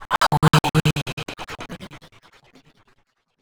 VOICEFX249_TEKNO_140_X_SC2(L).wav